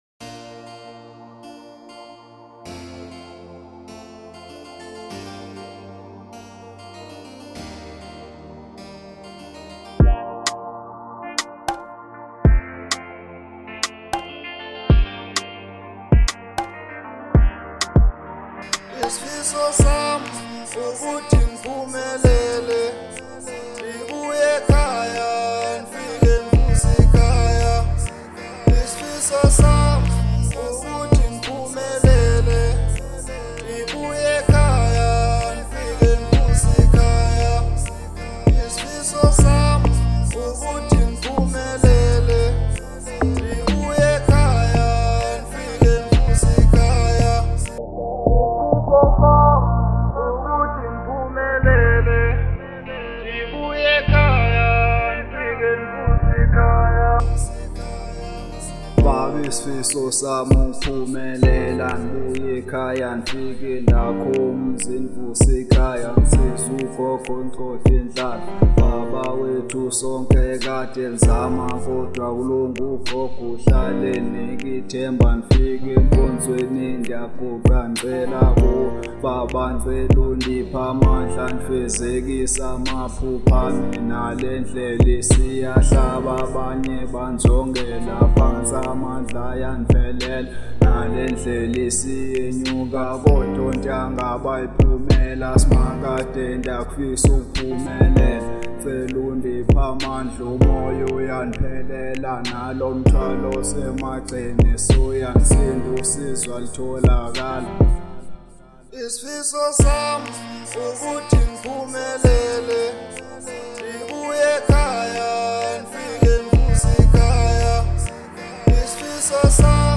03:57 Genre : Afro Pop Size